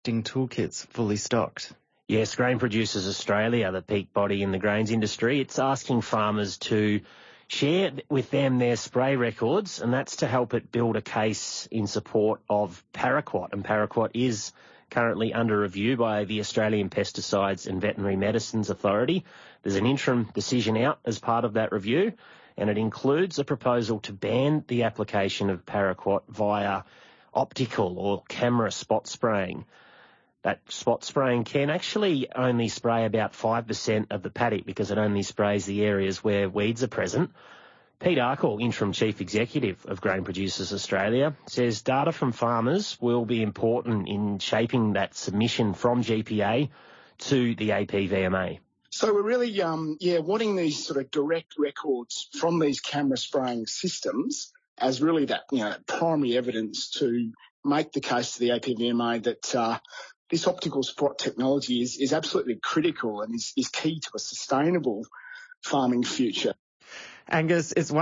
Speaking to ABC Radio National